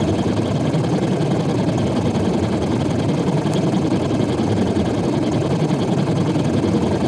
Future_engine_17_on.wav